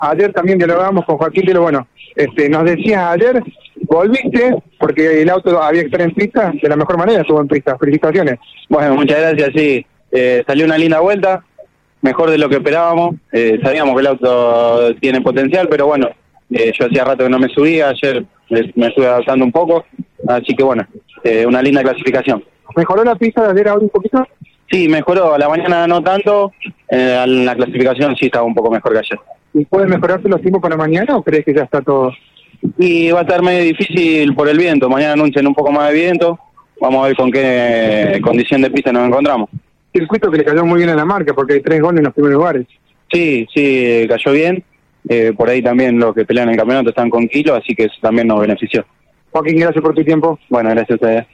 Por otra parte, también los demás protagonistas de la clasificación pasaron por nuestros micrófonos.